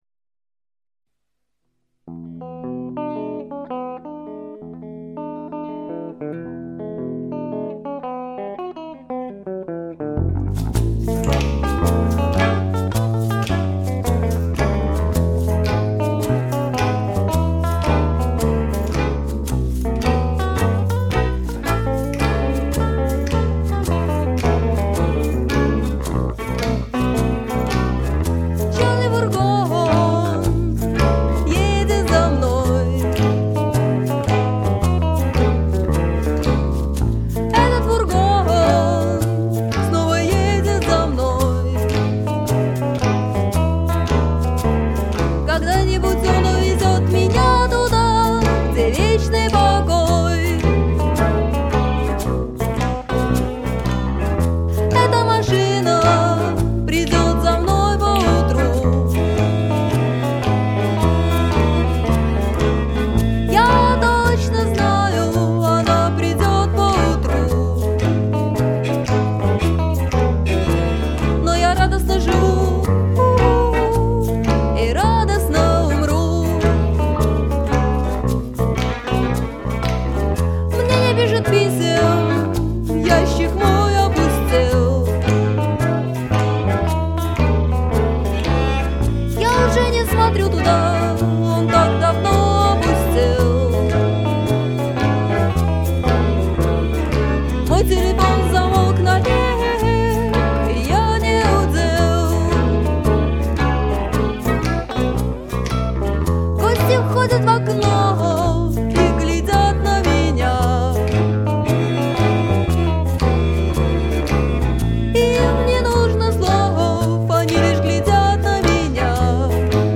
вокалы; эл. гитара [15]
роялопиано [10, 11, 15]